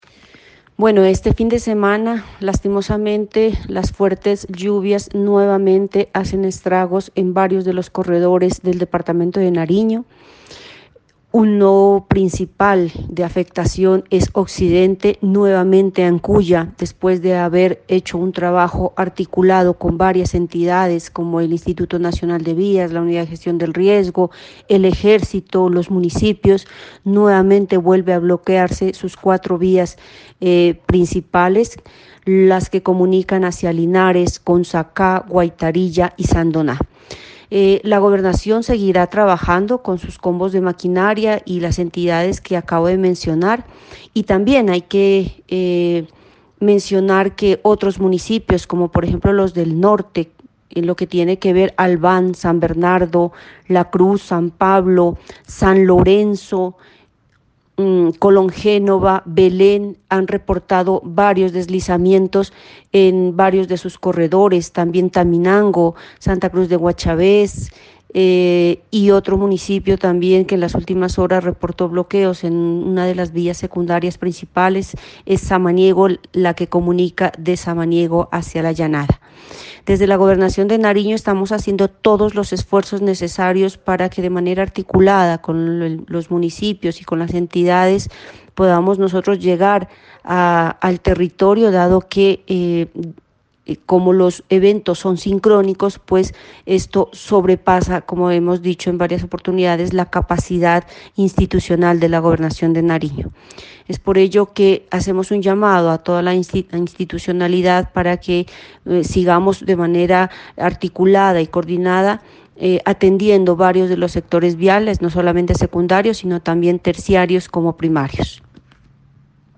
Declaración de la ingeniera Nilza Pantoja: